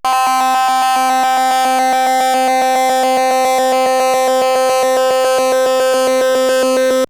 RANDOM FM 1.wav